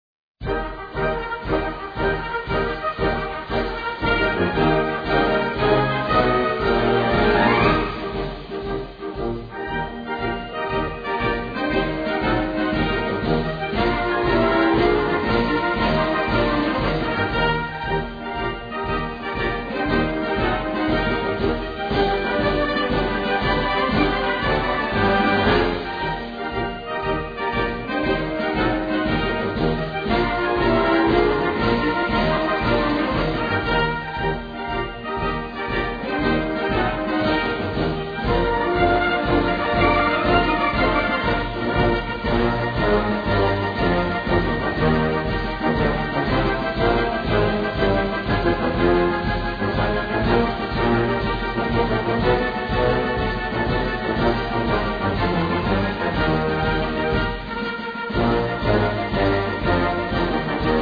Gattung: Marsch im 6/8-Takt
Besetzung: Blasorchester